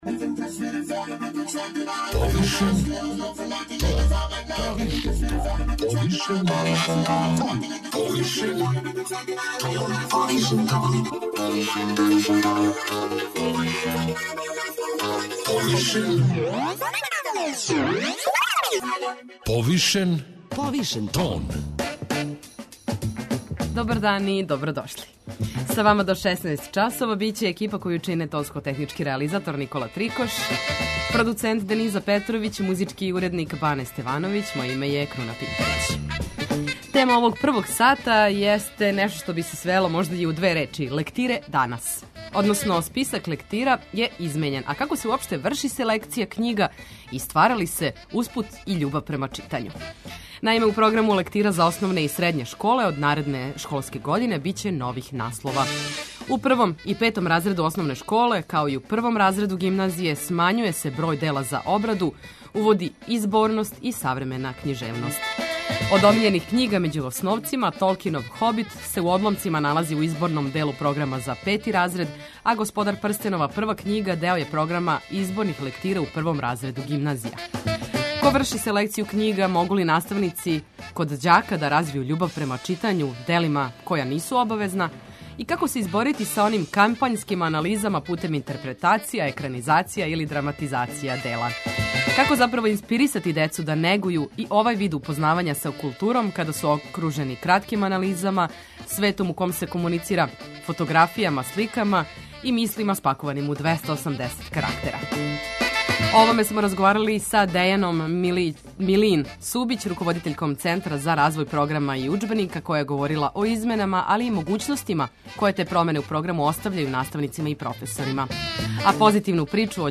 преузми : 52.31 MB Повишен тон Autor: Београд 202 Од понедељка до четвртка отварамо теме које нас муче и боле, оне о којима избегавамо да разговарамо aли и оне о којима разговарамо повишеним тоном.